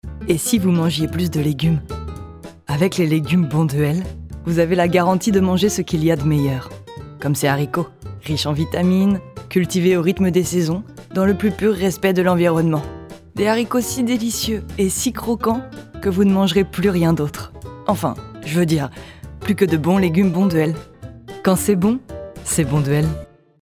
Voix off
- Mezzo-soprano